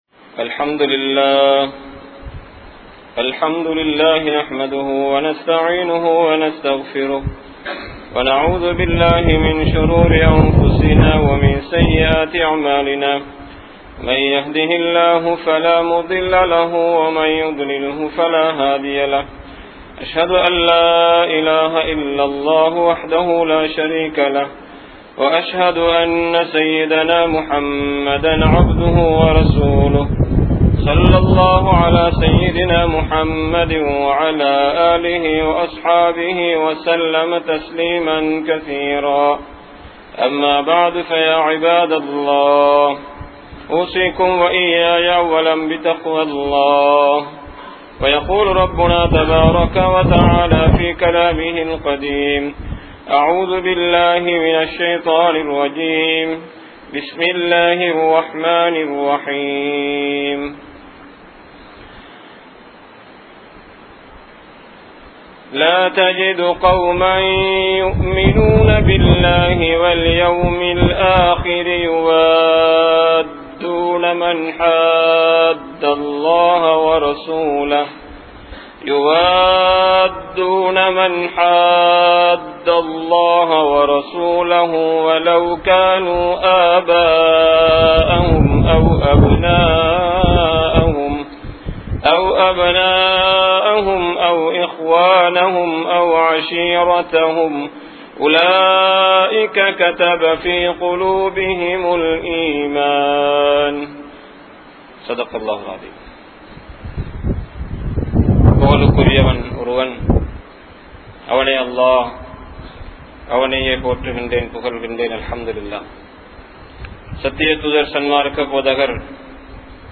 Nabi(SAW)Avarhalai Pin Pattrungal (நபி(ஸல்)அவர்களை பின்பற்றுங்கள்) | Audio Bayans | All Ceylon Muslim Youth Community | Addalaichenai